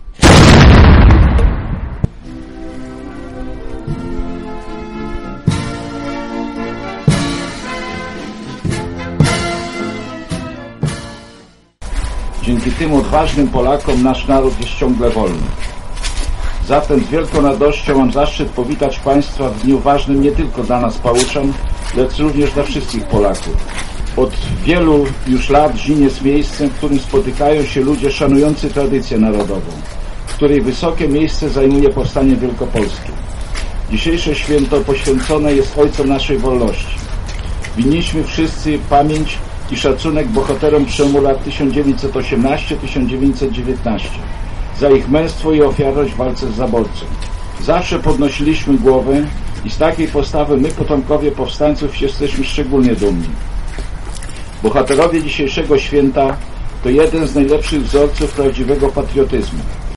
mówił w swoim przemówieniu Starosta Żniński Zbigniew Jaszczuk.